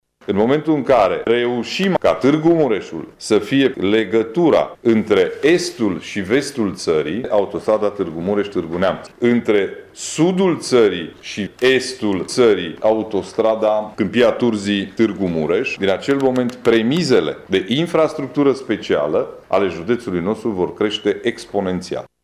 Semnalul de alarmă a fost tras ieri, într-o conferinţă de presă, de președintele CJ Mureș, Ciprian Dobre.
Judeţul Mureș va deveni un nod de autostrăzi dacă se va intersecta cu autostrada Borș – Tîrgu-Mureș – Brașov, a explicat Ciprian Dobre: